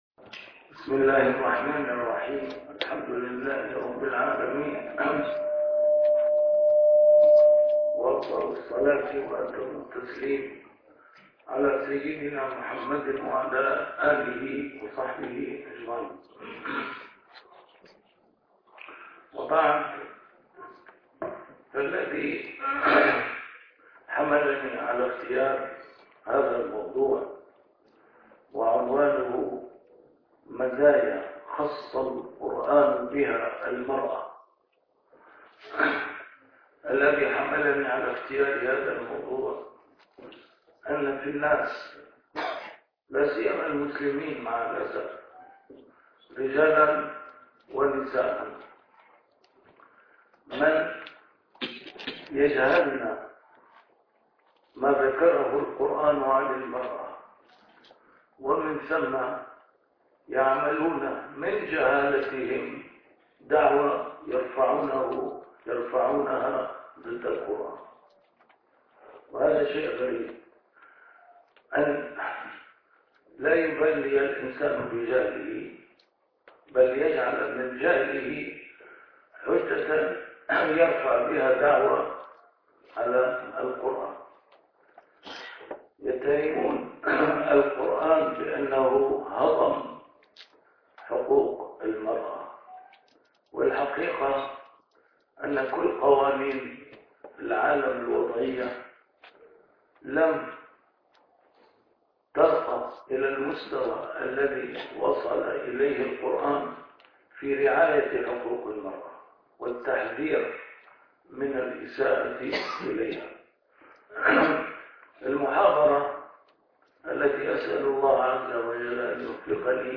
A MARTYR SCHOLAR: IMAM MUHAMMAD SAEED RAMADAN AL-BOUTI - الدروس العلمية - محاضرات متفرقة في مناسبات مختلفة - مزايا اختص الله به المرأة في القرآن | محاضرة في فرنسا